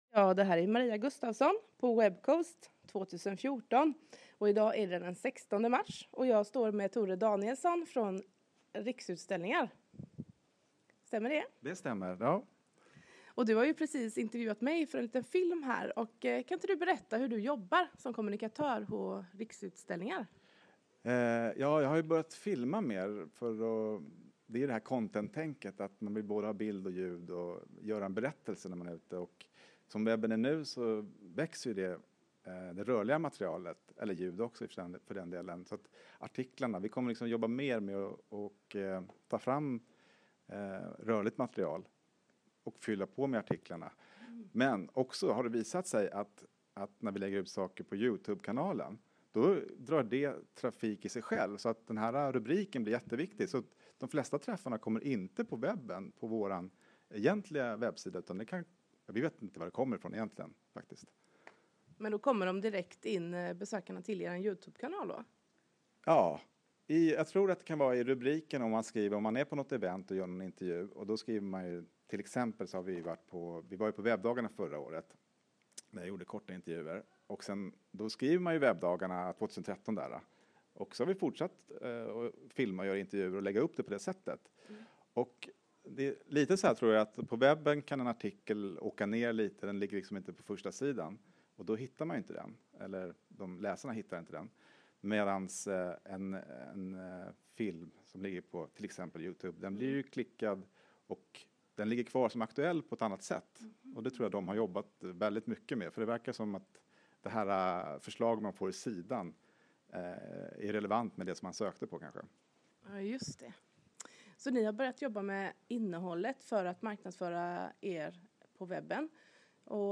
Pratstund